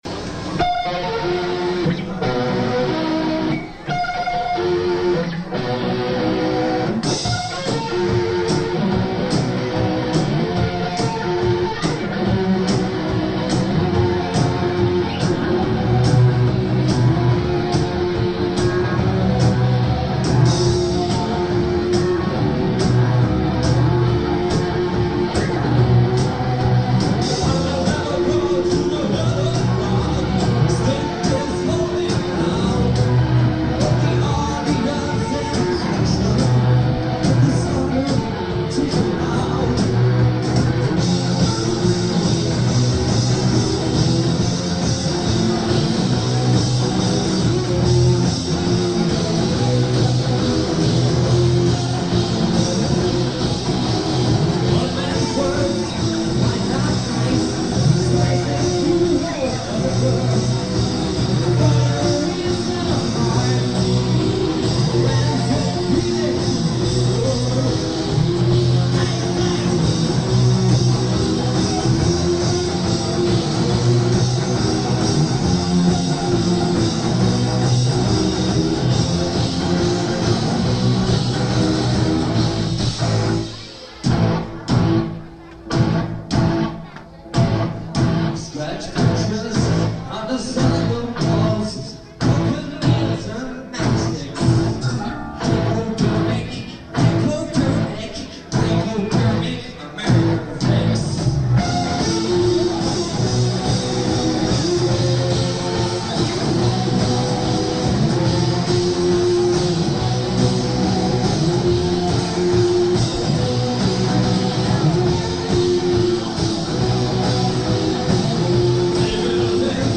at the Marble Bar in Baltimore back in 1982